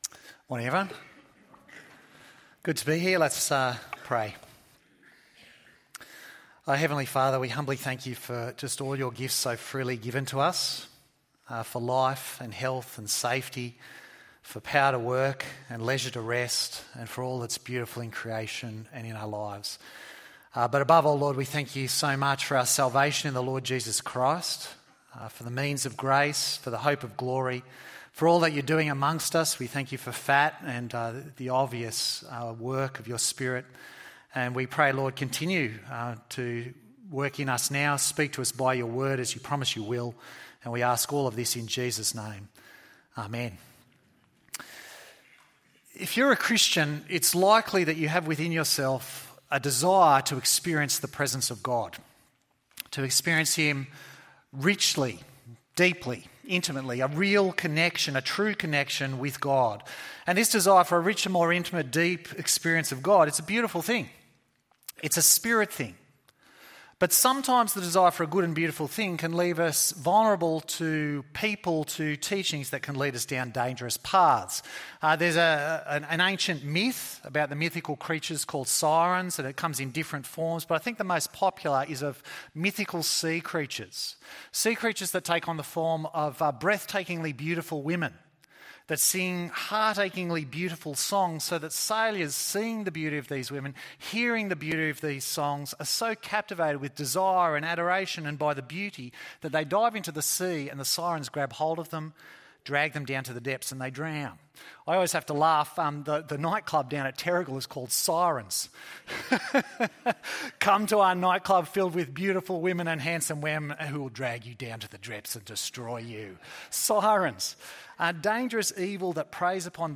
Experiencing God ~ EV Church Sermons Podcast